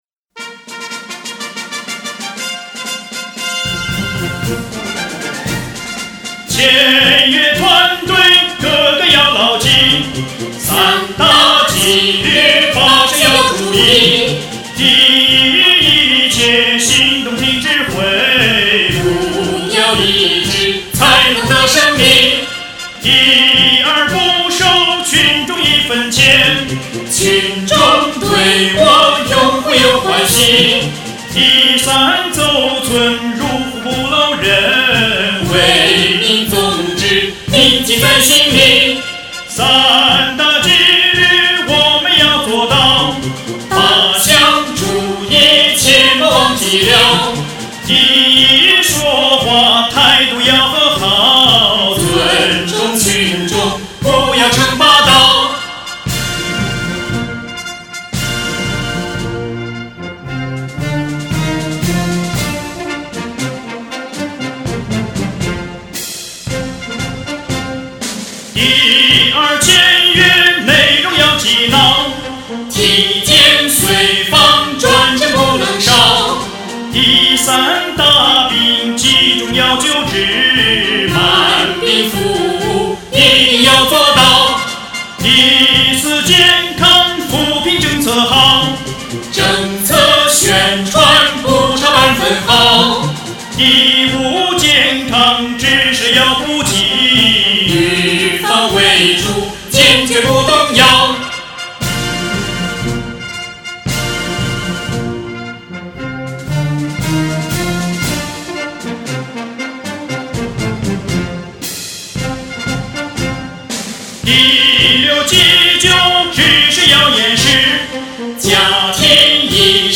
三大纪律八项注意-健康扶贫攻坚战之歌(带合唱).mp3